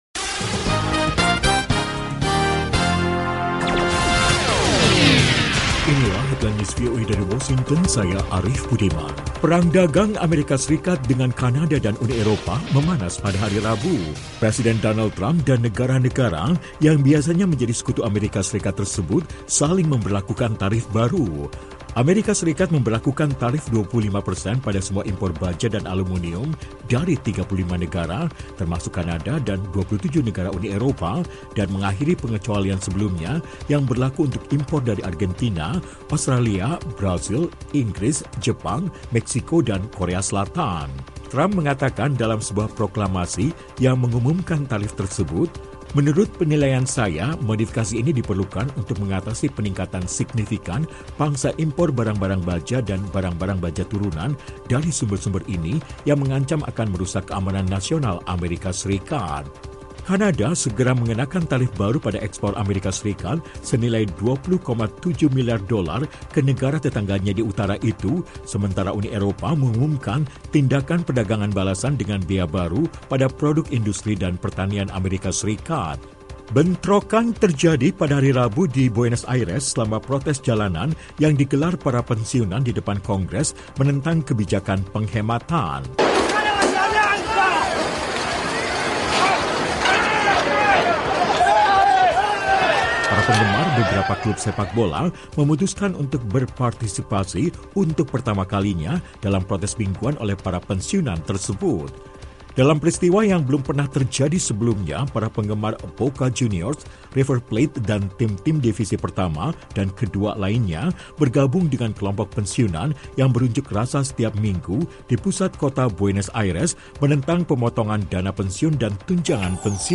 Laporan Radio VOA Indonesia